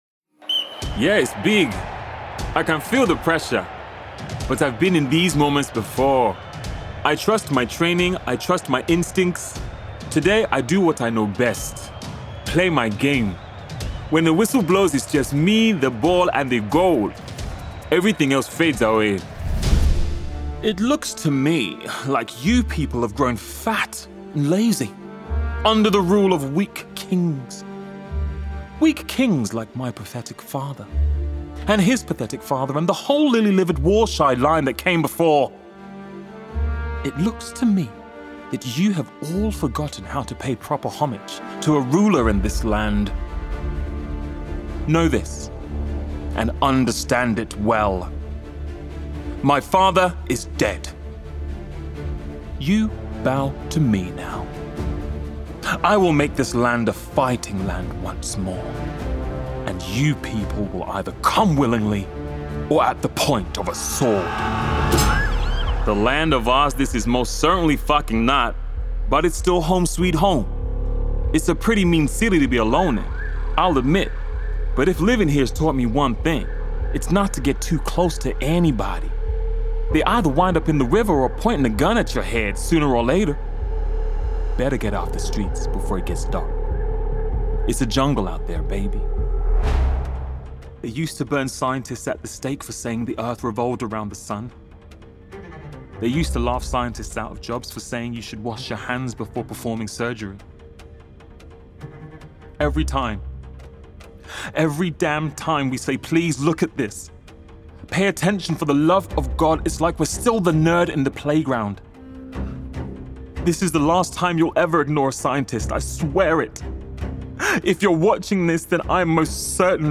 • Native Accent: London